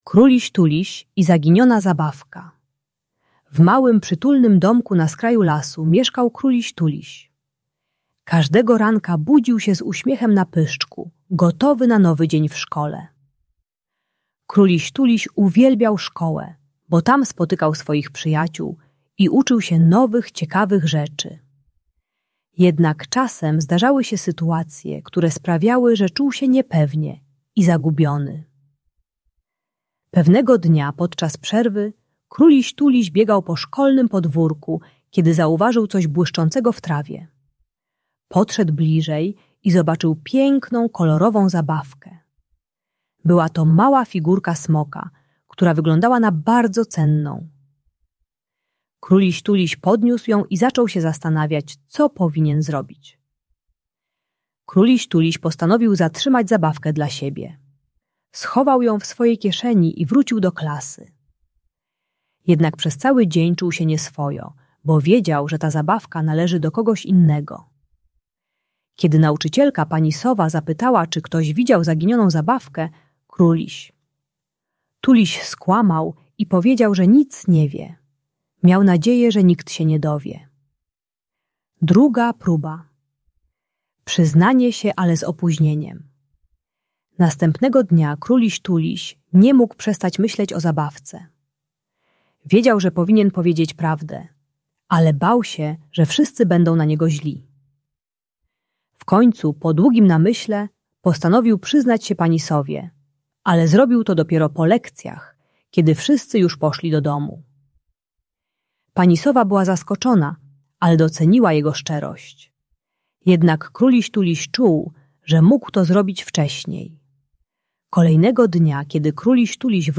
Audiobajka dla przedszkolaków 4-6 lat o szczerości i uczciwości. Pomaga dziecku zrozumieć, dlaczego warto mówić prawdę od razu, nawet gdy jest to trudne.